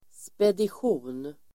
Ladda ner uttalet
Uttal: [spedisj'o:n]
spedition.mp3